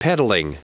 Prononciation du mot peddling en anglais (fichier audio)
Prononciation du mot : peddling